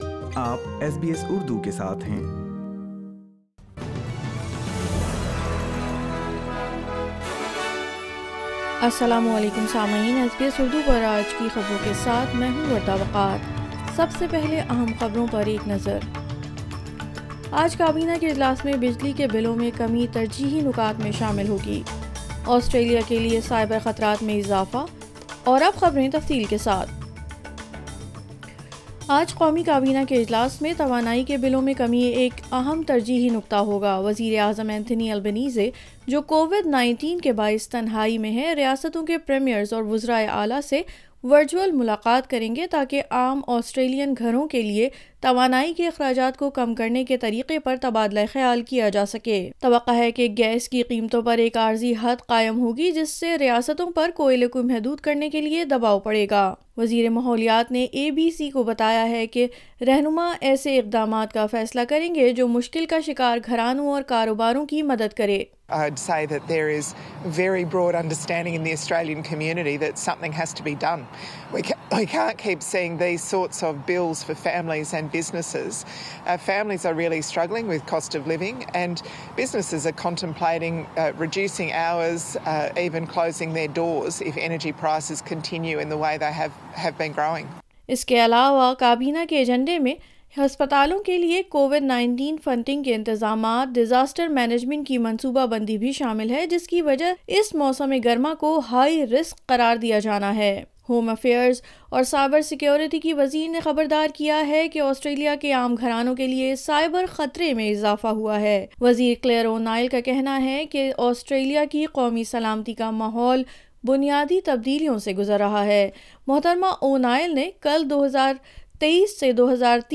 Urdu News Friday 09 December 2022